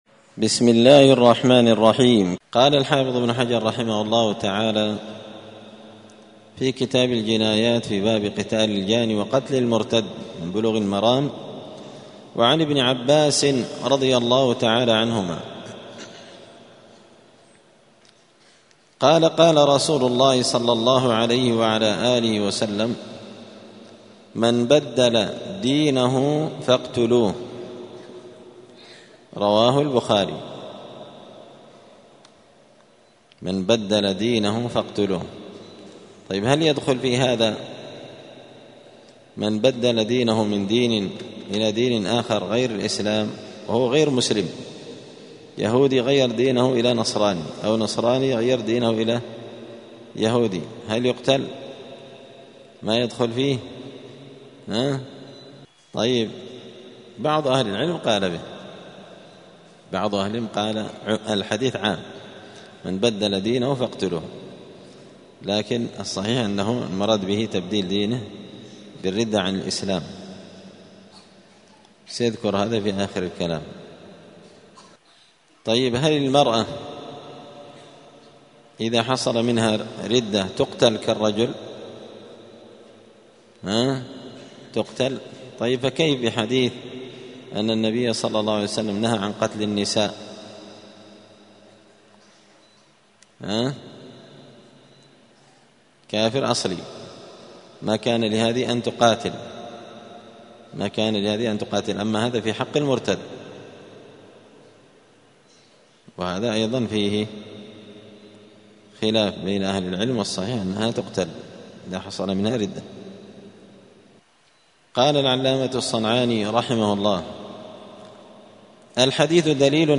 *الدرس الأربعون (40) {باب استتابة المرتد}*